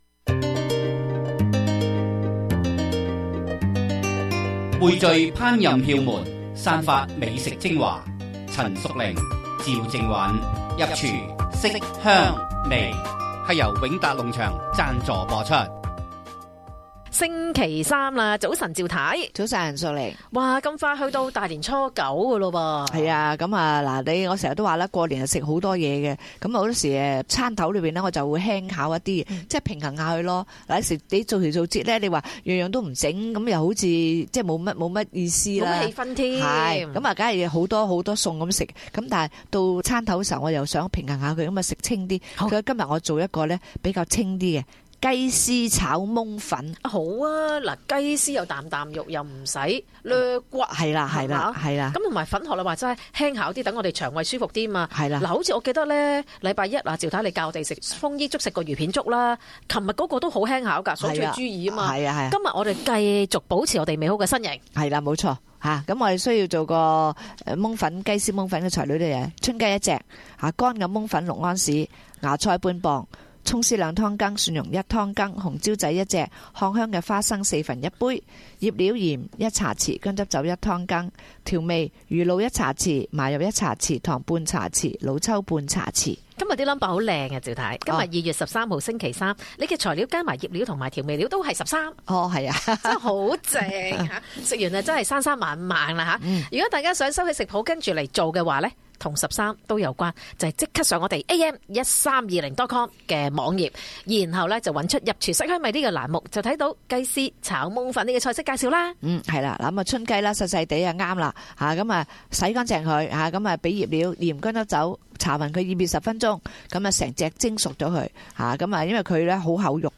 This radio program is broadcasted in Cantonese.